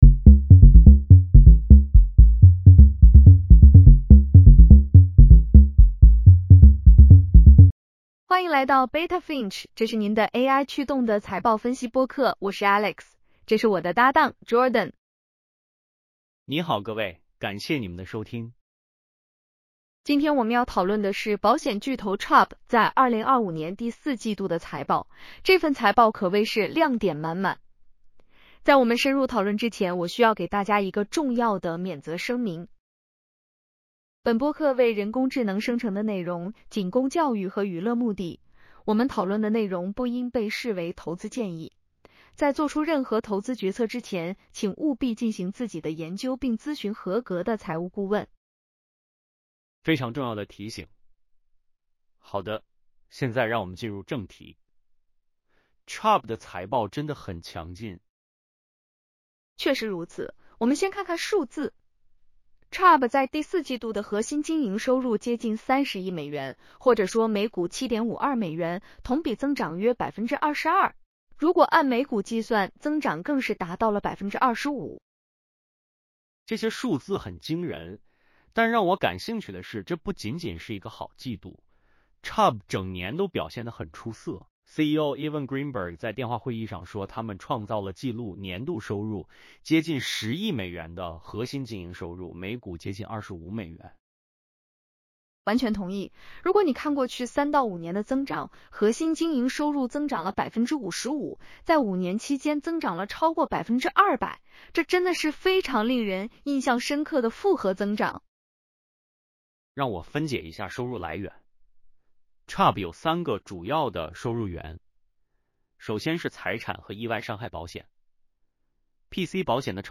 欢迎来到Beta Finch，这是您的AI驱动的财报分析播客。